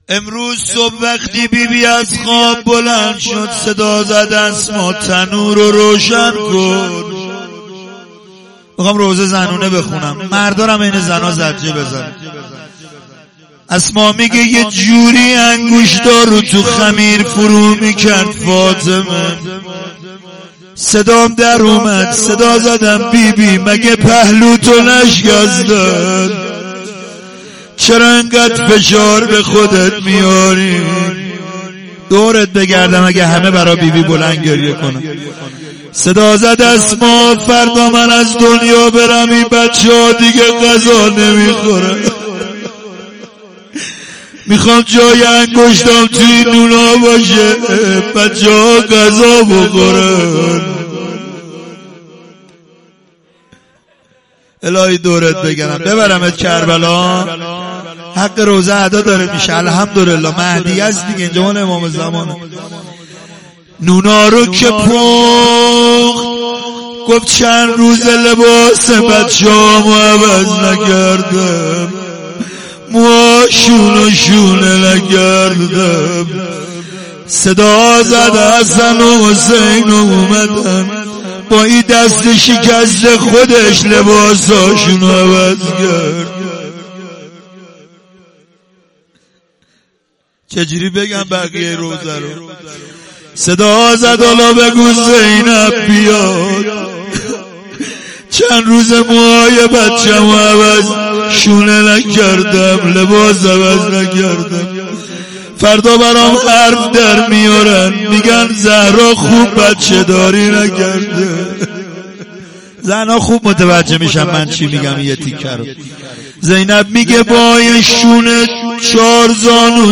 روضه۱.mp3